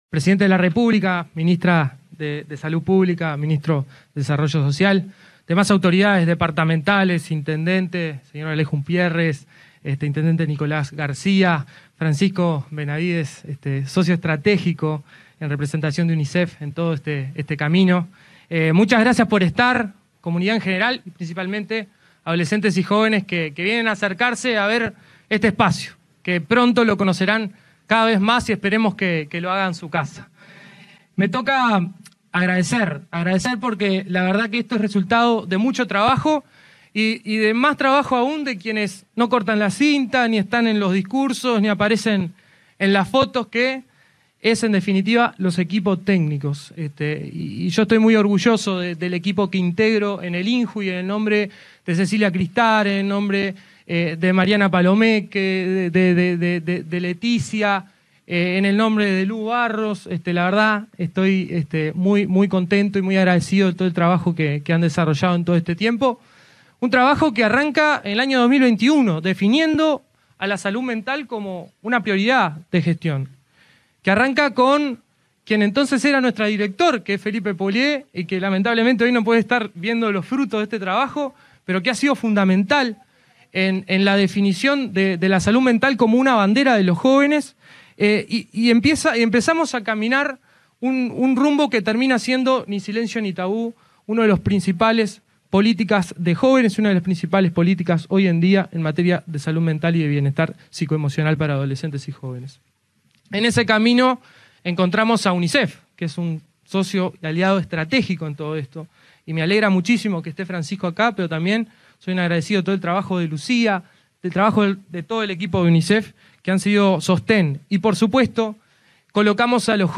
Palabras del director del INJU, Aparicio Saravia
Durante la inauguración del primer centro Ni Silencio Ni Tabú, en Rocha, que se focalizará en el trabajo con adolescentes y jóvenes de entre 14 y 29